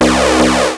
RetroGamesSoundFX / Hum / Hum14.wav
Hum14.wav